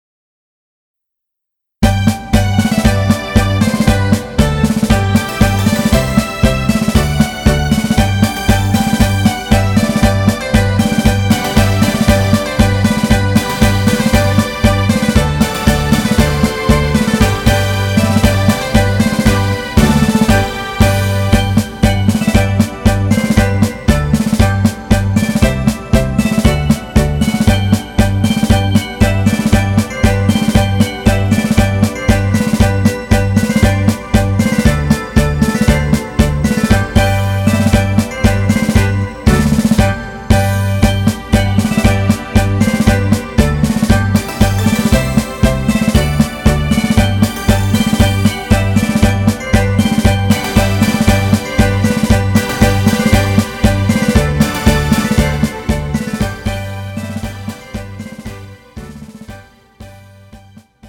음정 G 키
장르 가요 구분 Pro MR